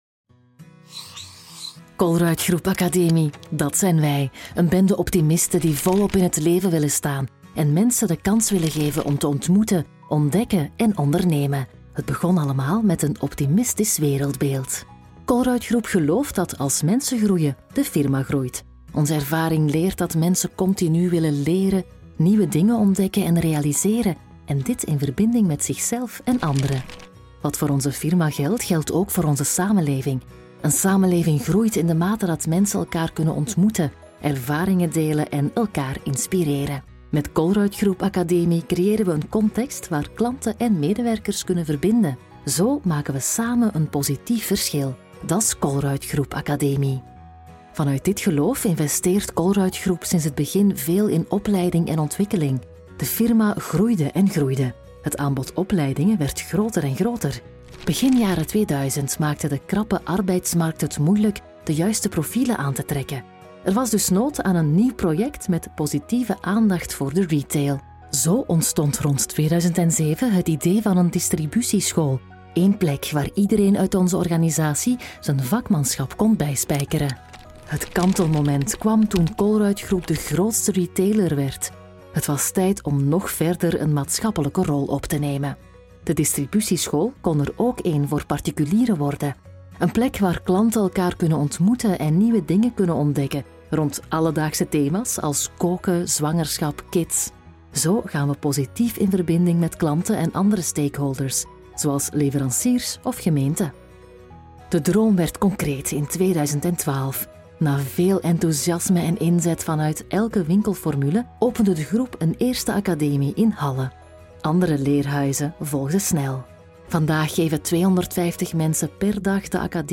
Vlaams
Vertrouwd, Vriendelijk, Natuurlijk
Corporate